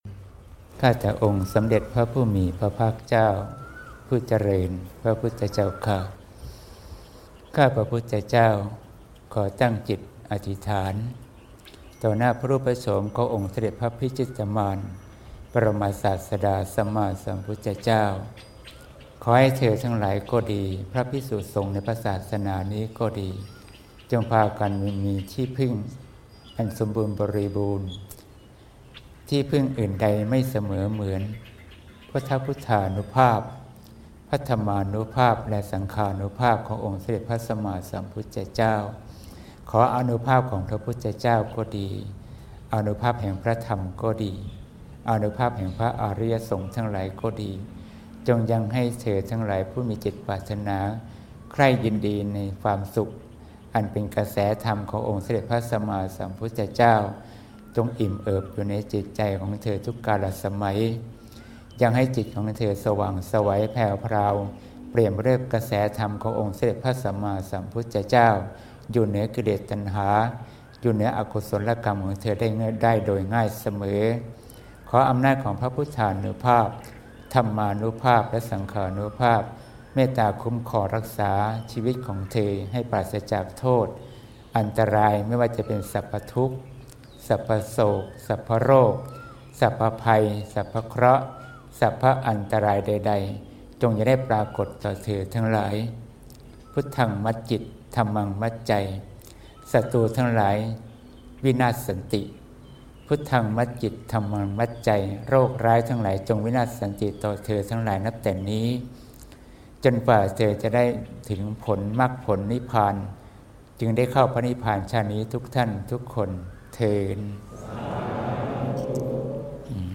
หลวงพ่อให้พร : วันที่ ๑ มกราคม ๒๕๖๘